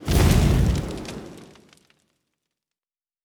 Fire Spelll 30.wav